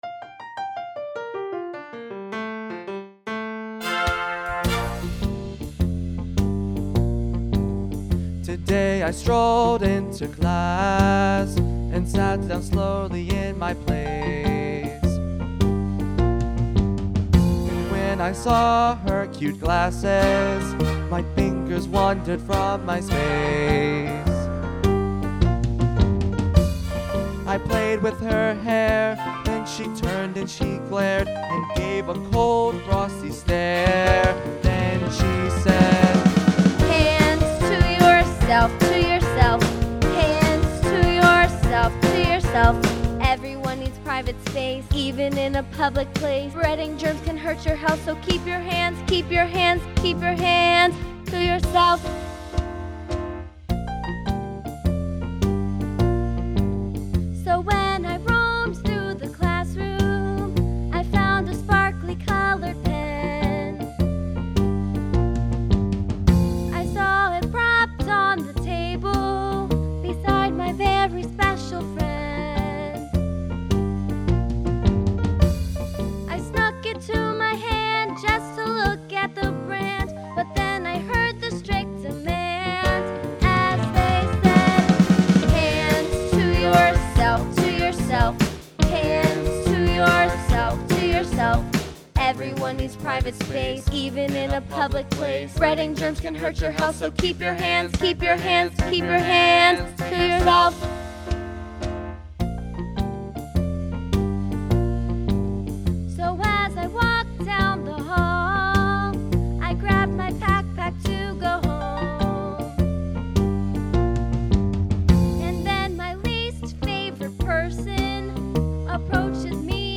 MP3 (with singing)